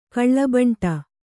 ♪ kaḷḷabaṇṭa